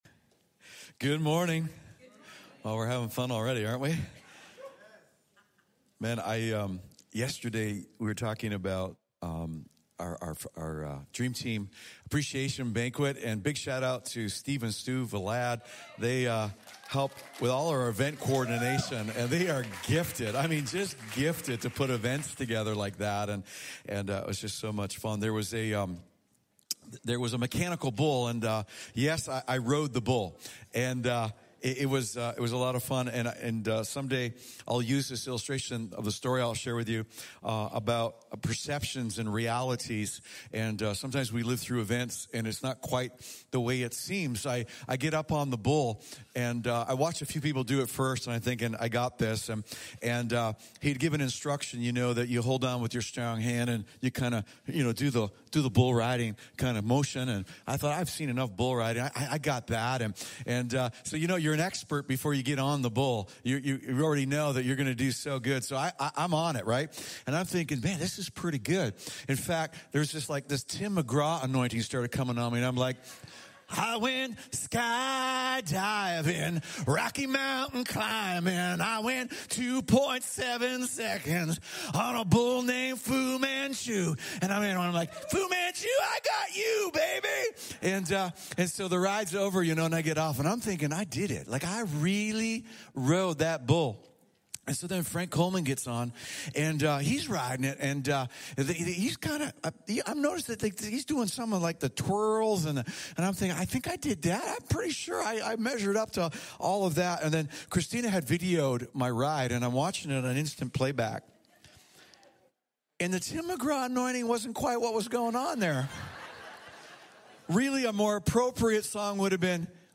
The latest messages from Harvest's weekend service in Cornwall Ontario